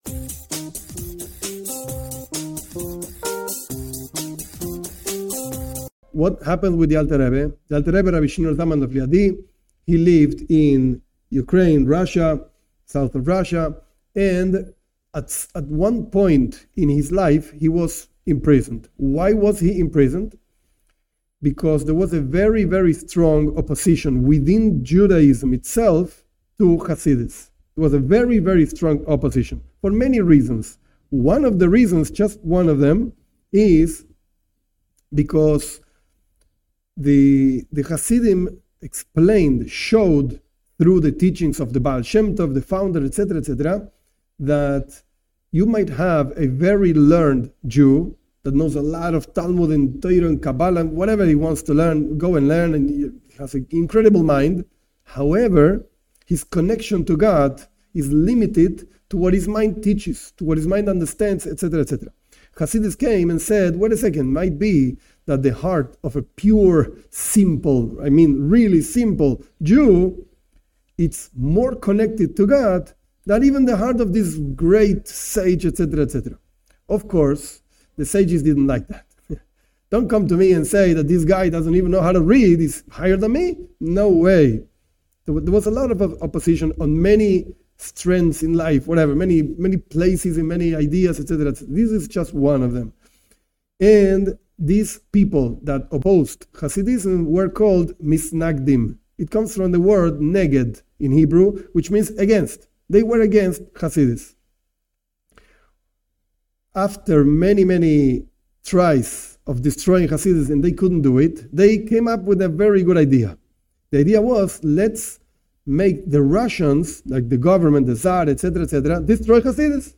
This class is one part of the introduction to learning Chassidut. This class explains briefly the story of the 19th of Kislev. The complete class is a review of the text of a Talk by The Rebbe on the 19th of Kislev (1983-1984) where the Rebbe explains a letter written by the founder of Chabad, Rabbi Shneur Zalman of Liadi, regarding his liberation from the imprisonment in Zarist Russia in 1789.